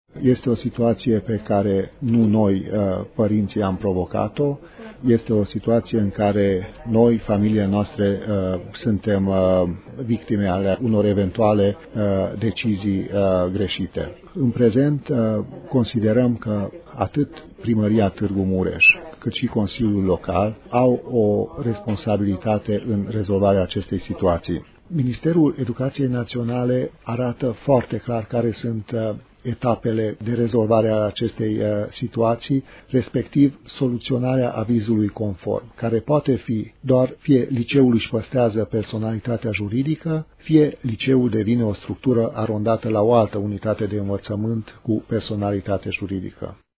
Nemulțumiți de tergiversarea problemei, părinții elevilor Liceului Teologic Romano-Catolic s-au constituit într-un grup civic. Aceștia au anunțat azi, într-o conferință de presă, că săptămâna viitoare vor protesta zilnic față de situația creată.